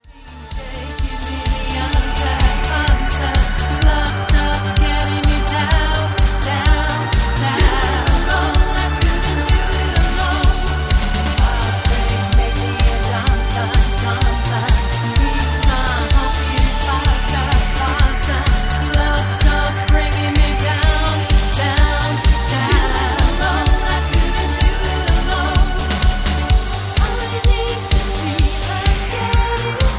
Gm
MPEG 1 Layer 3 (Stereo)
Backing track Karaoke
Pop, Disco, 2000s